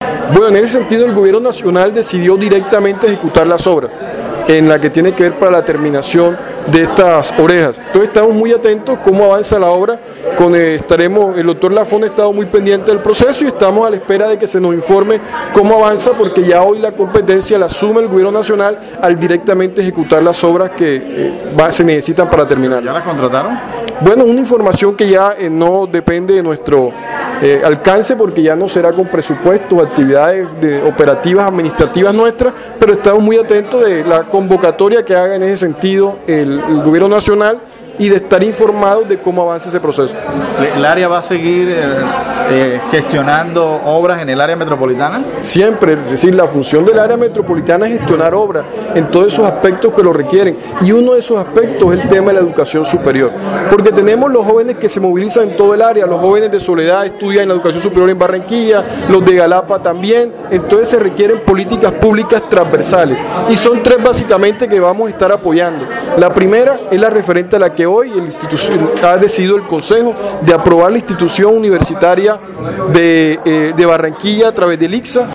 La contratación de orejas y del puente de la carrera 53 la hará de manera directa el Gobierno Nacional, pero nosotros a través de la Secretaria de Infraestructura estamos atentos para que este proyecto se termine cuanto antes, reiteró Berdugo en diálogo con Atlántico en Noticias.